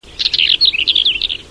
głosy innych białorzytek